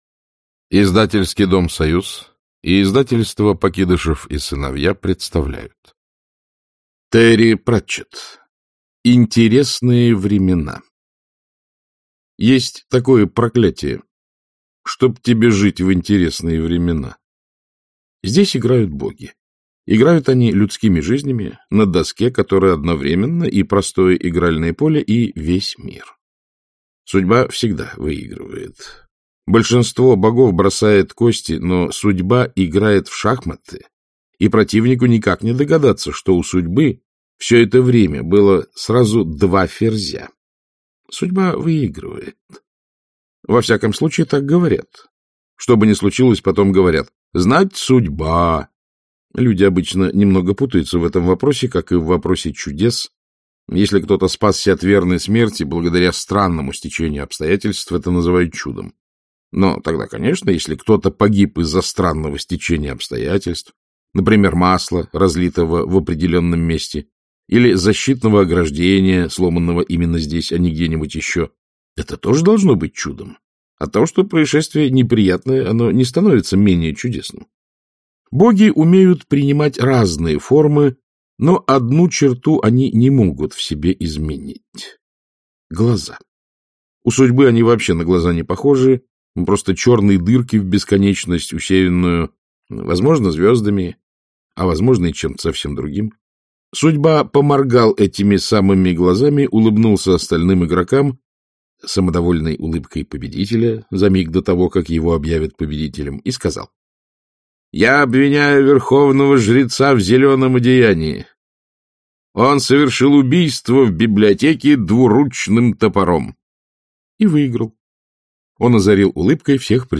Аудиокнига Интересные времена | Библиотека аудиокниг